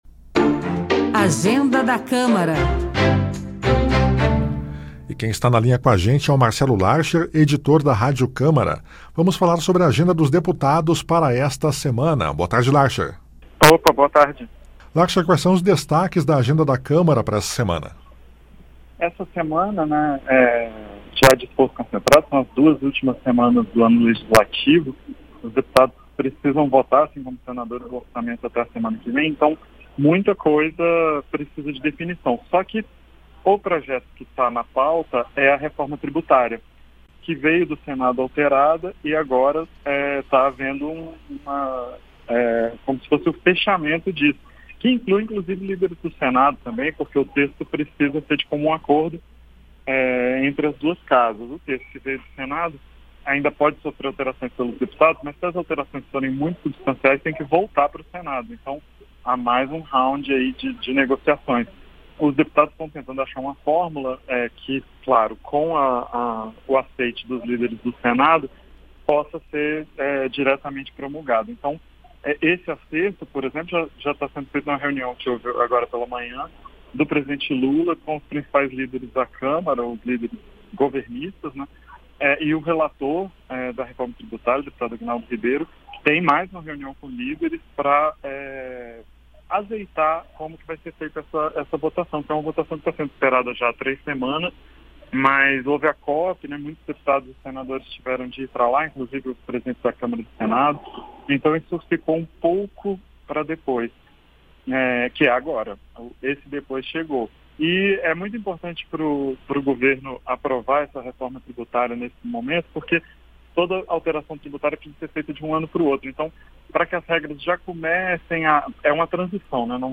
Programa jornalístico que trata da agenda do Congresso Nacional, com entrevistas, comentários e reportagens sobre os principais assuntos em debate e em votação.